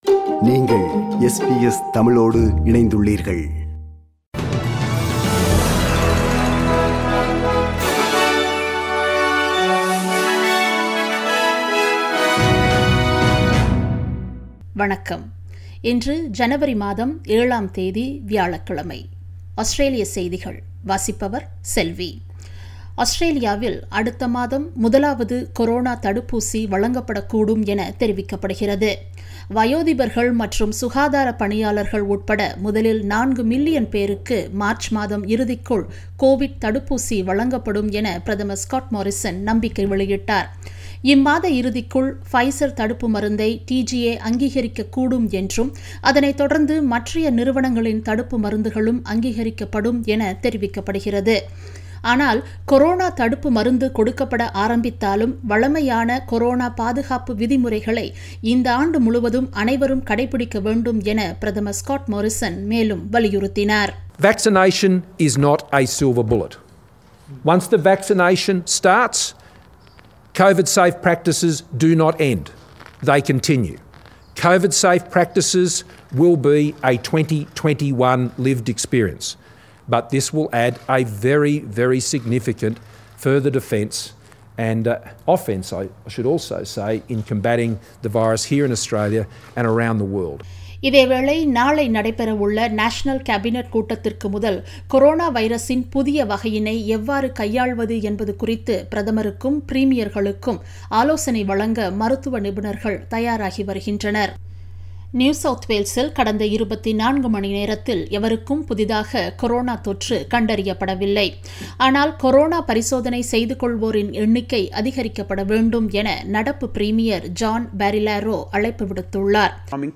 ஆஸ்திரேலிய செய்திகள்